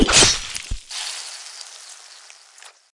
Звуки включают механические элементы работы устройства и фоновые атмосферные эффекты.
Звук гильотины отсекающей голову и брызги крови